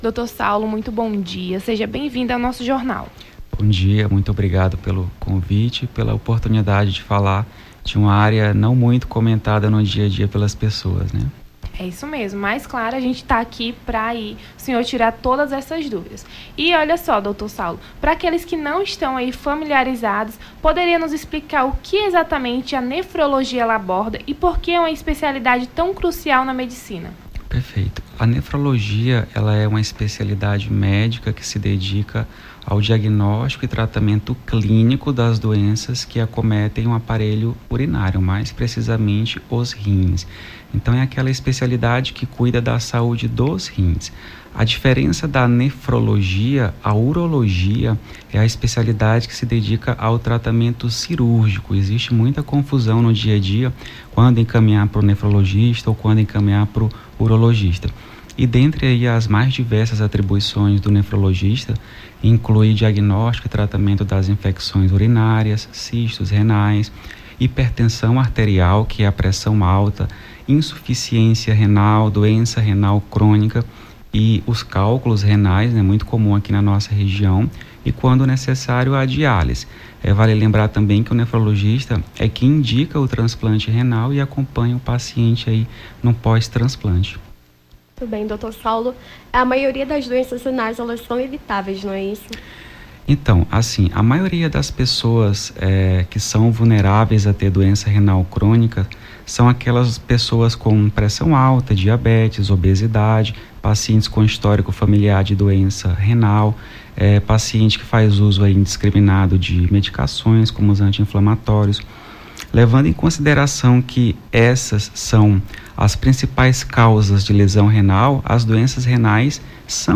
No Jornal da Manhã desta quinta-feira(16), as apresentadoras